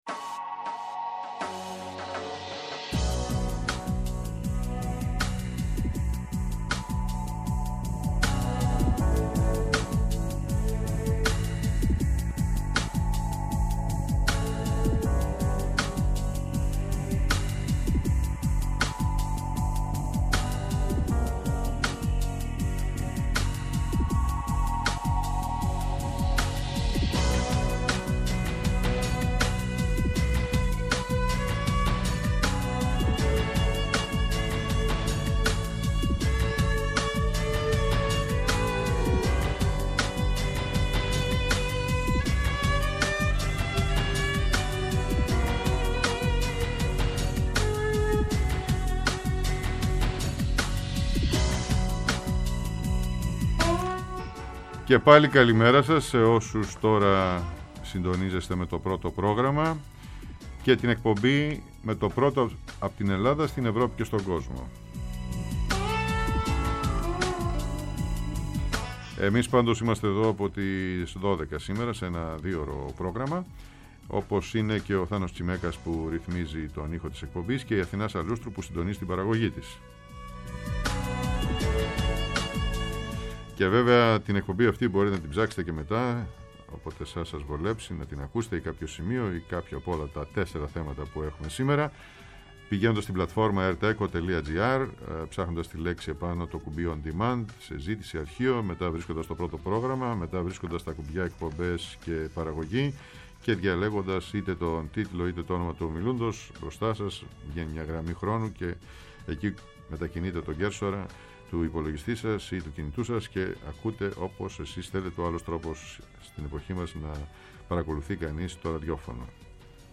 Ο Ακαδημαϊκός και διαστημικός επιστήμονας Σταμάτης Κριμιζής προσκεκλημένος σήμερα στο Πρώτο Πρόγραμμα